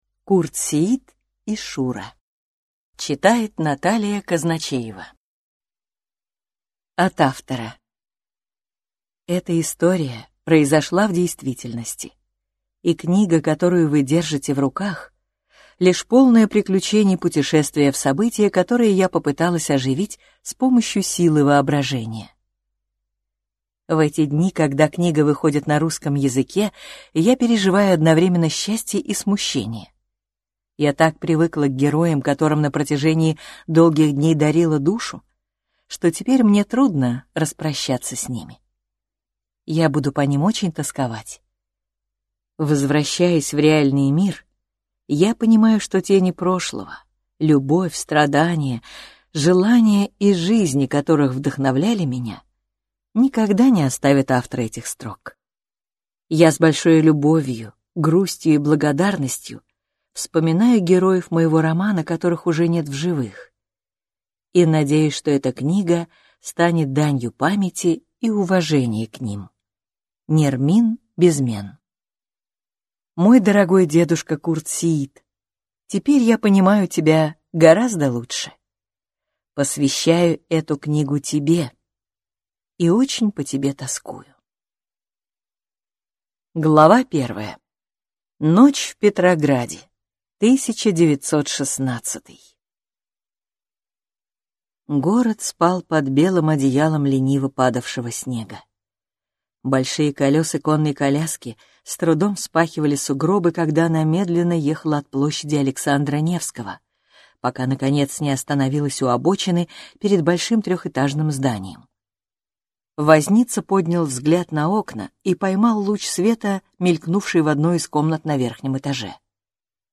Аудиокнига Курт Сеит и Александра | Библиотека аудиокниг